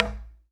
Knock29.wav